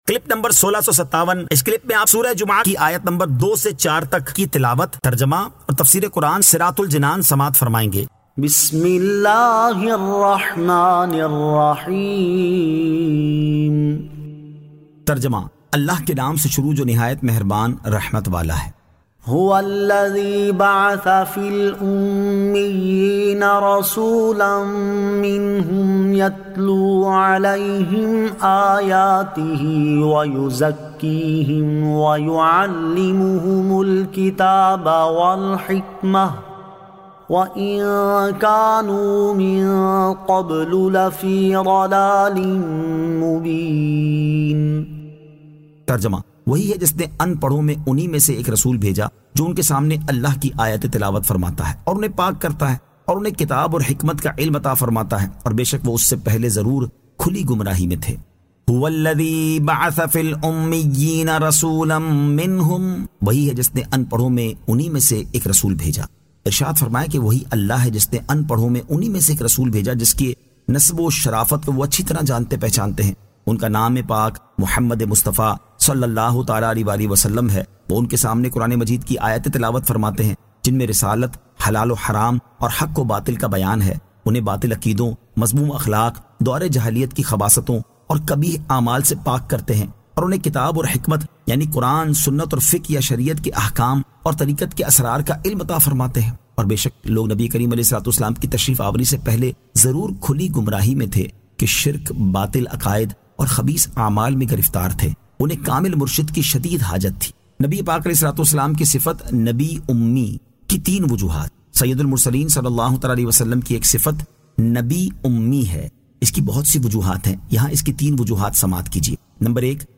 Surah Al-Jumu'ah 02 To 04 Tilawat , Tarjama , Tafseer